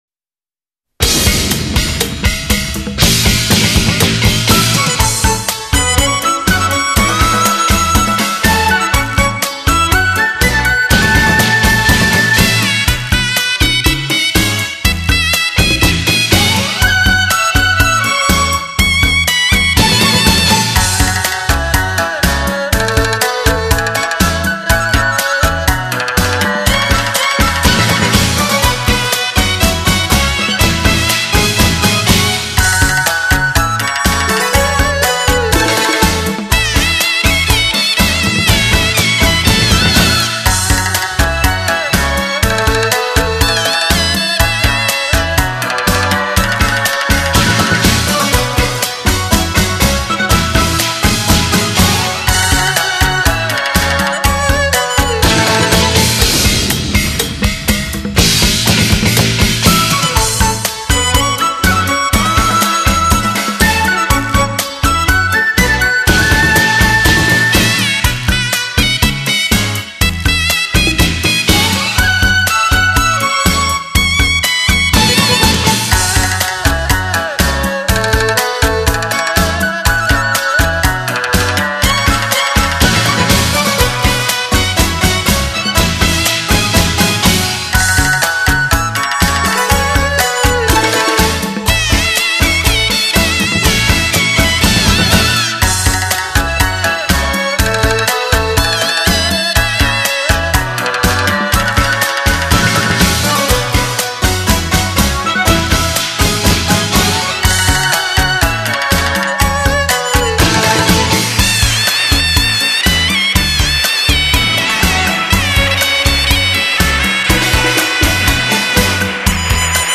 欢悦喧天迎喜庆 铿锵华乐喜纳福